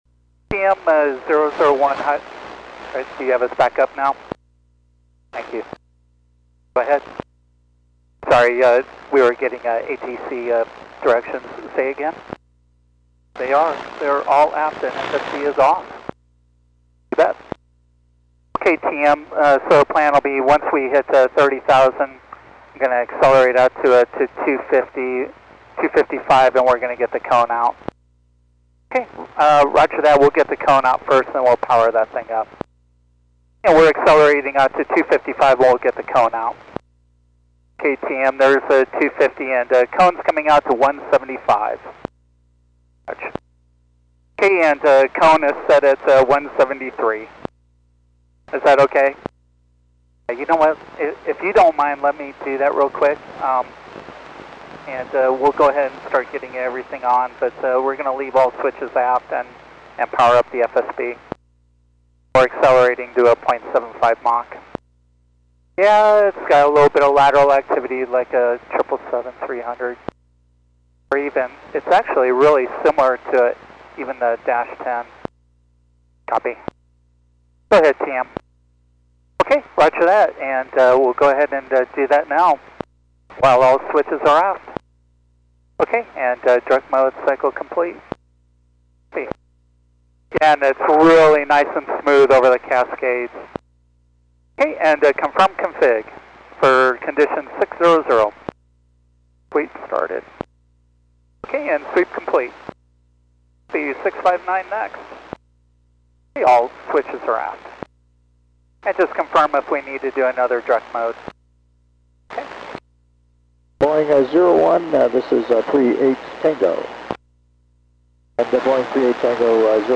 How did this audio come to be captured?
Comms with the telemetry room here.